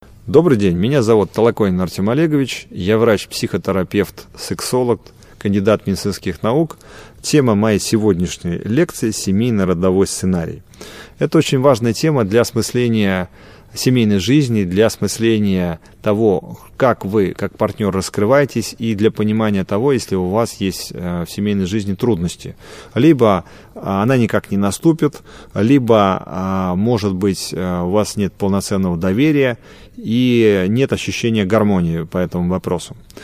Аудиокнига Семейно-родовой сценарий | Библиотека аудиокниг